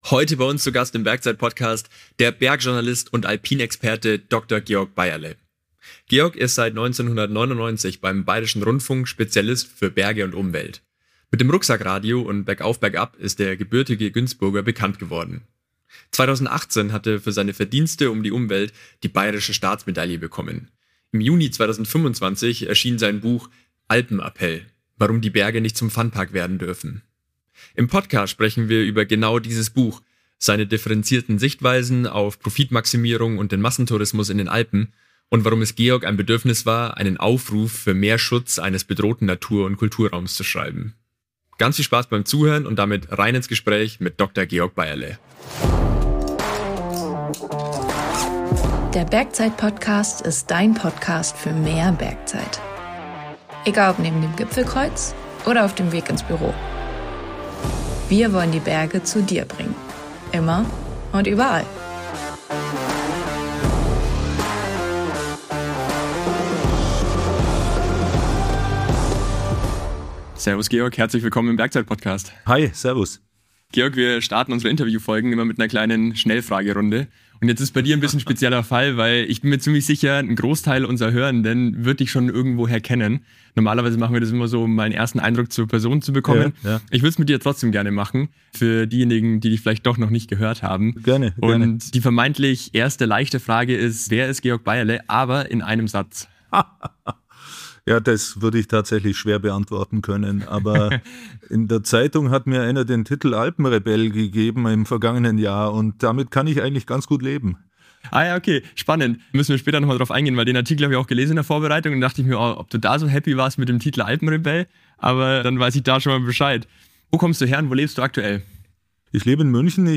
#104 Interview